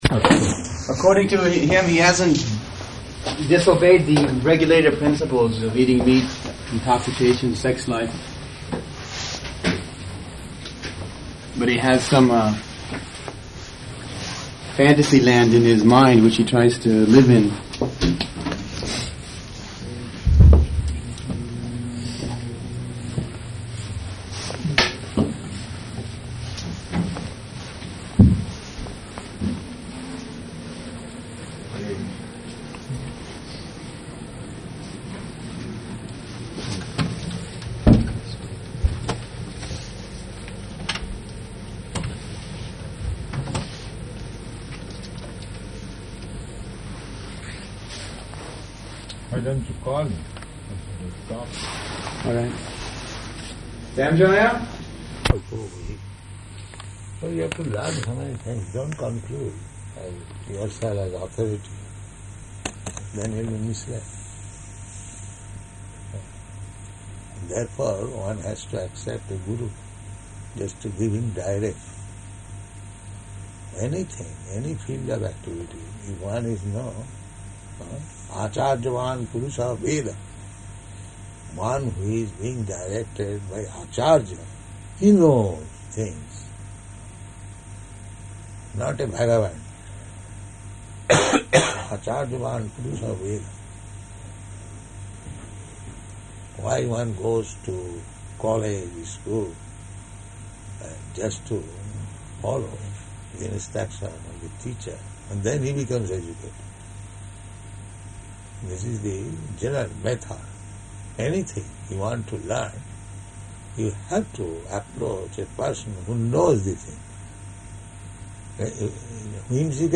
Room Conversation
Type: Conversation
Location: Tokyo